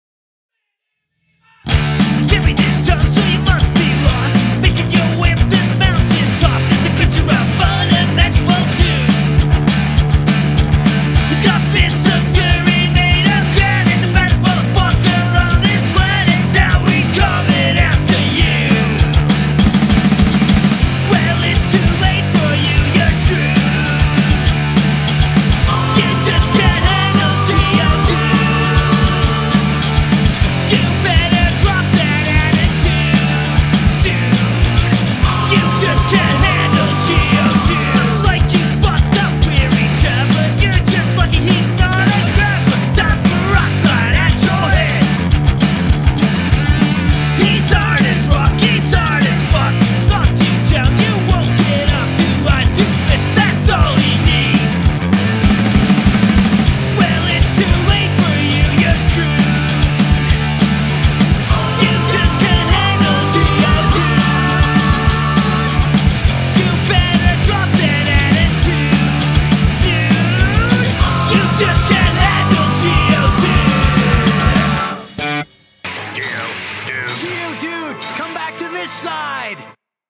90s Influenced Punk Rock Tracks
We are both pretty new at home recording, so would appreciate any feedback on each mix.